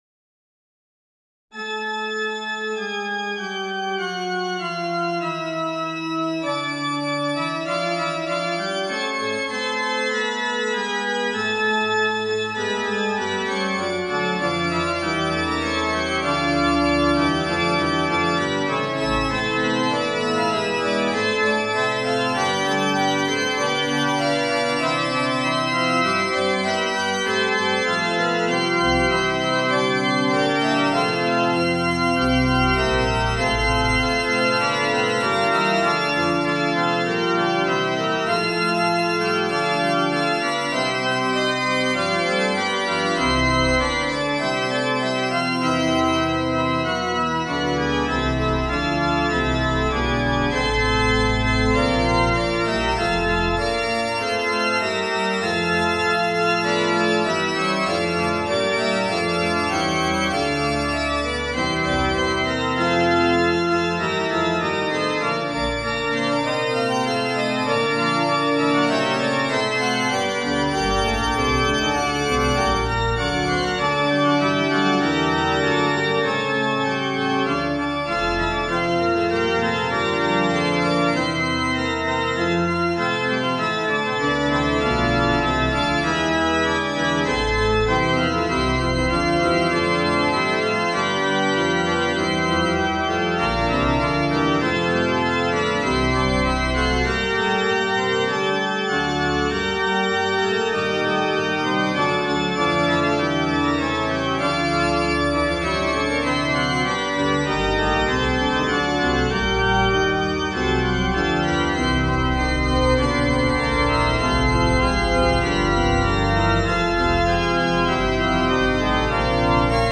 学習フーガ
上のフーガを、組み合えたり、繋ぎを追加したりしたものです。
好みもありますが,すこし聴き飽きないものになったかもしれません。